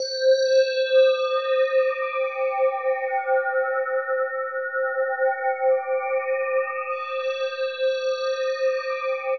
Index of /90_sSampleCDs/Chillout (ambient1&2)/11 Glass Atmos (pad)